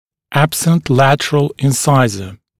[‘æbsənt ‘lætərəl ɪn’saɪzə] [‘эбсэнт ‘лэтэрэл ин’сайзэ]отсутствующий латеральный резец